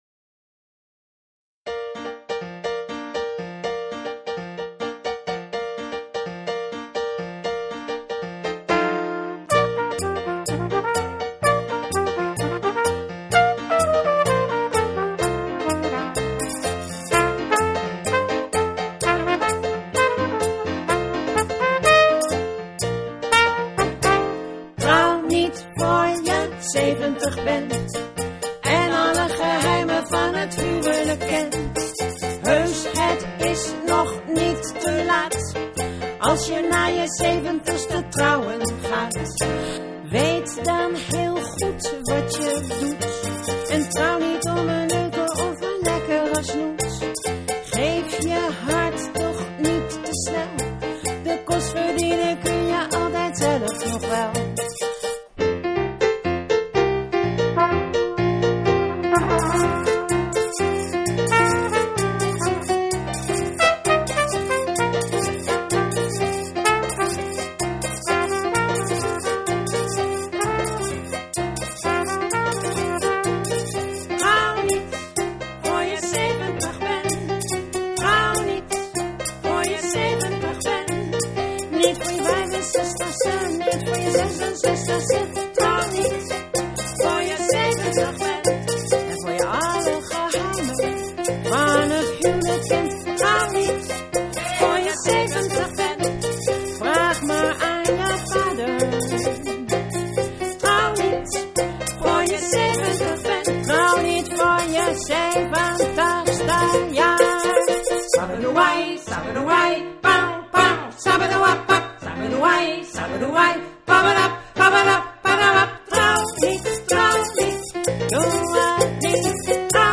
neder-salsa met een knipoog
trompet, zang, handpercussie
piano, zang, voetpercussie